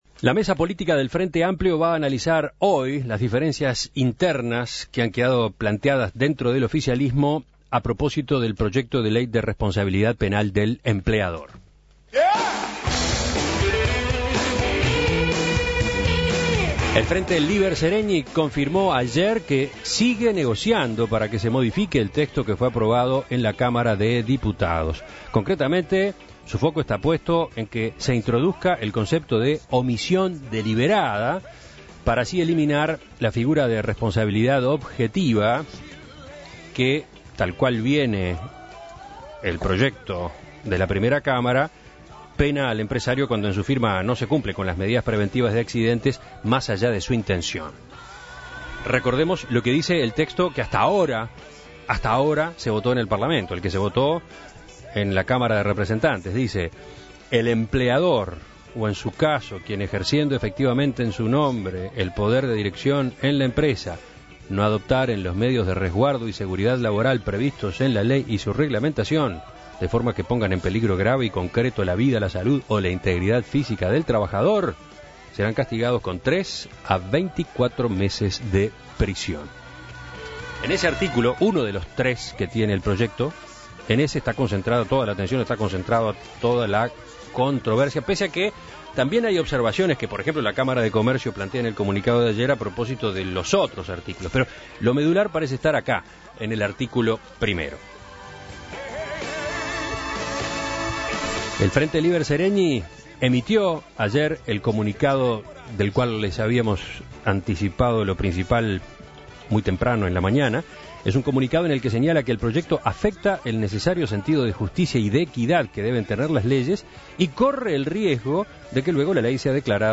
El Frente Líber Seregni (FLS) pretende modificar el proyecto tal cual se aprobó en Diputados. El senador del FLS Rafael Michelini sostuvo, en diálogo con En Perspectiva, que la ley tal cual está no representa al mundo del trabajo en su totalidad.